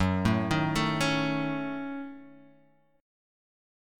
Gbm6 chord